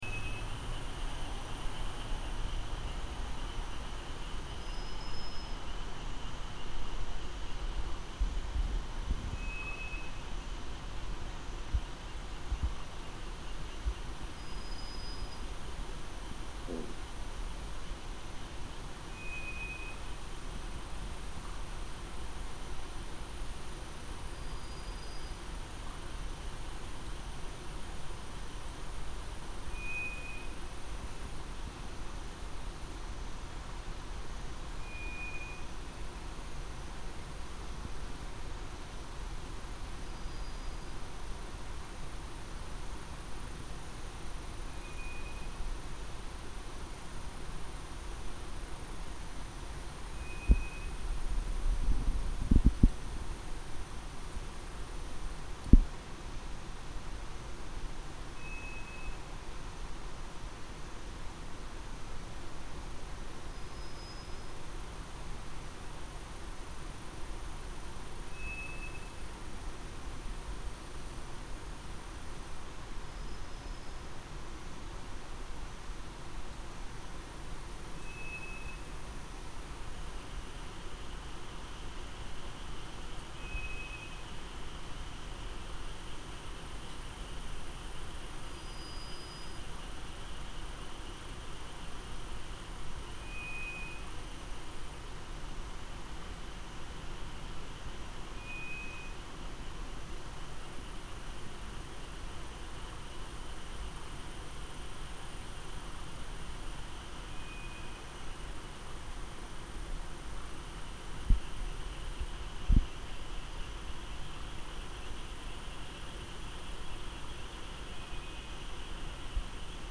10月に入り夜になると「ヌエ」が泣きながら近づいたり遠のいたりしています。「ヌエ」とは空想の動物で実際はトラツグミと言う野鳥の声です。
トラツグミの声です。